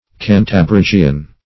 \Can`ta*brig"i*an\